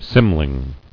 [cym·ling]